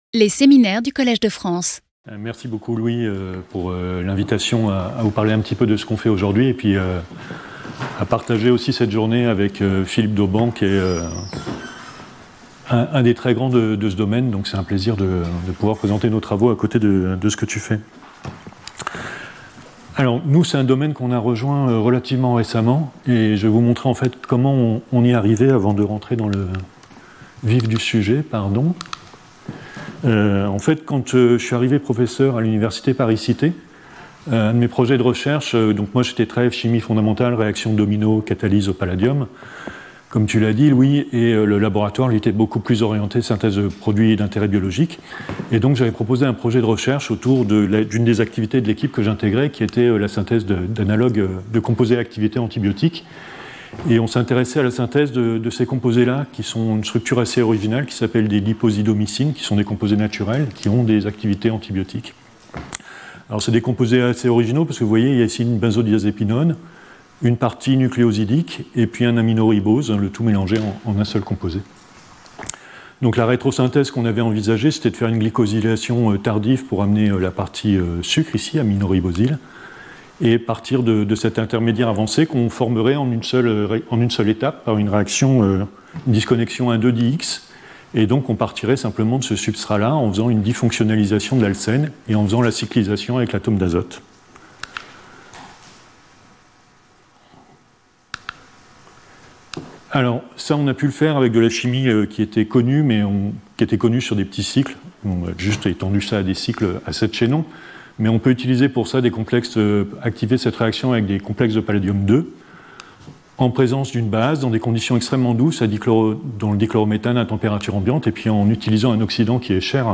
In this lecture, we will discuss our latest results on the selective amination of different classes of C-H bonds (from benzylic to non-activated bonds), which were made possible by the discovery of highly discriminating rhodium nitrenes .3 a) Chu, J.C.K.; Rovis, T., Angew.